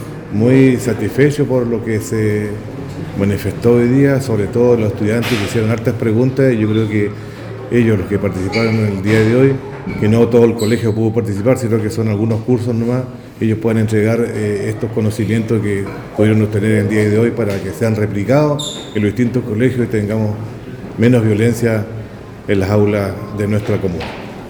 Por su parte, el Alcalde Baltazar Elgueta, indicó:
Alcalde-por-seminario-jovenes.mp3